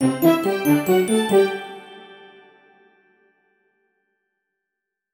Sound_PeopleLevelUp.mp3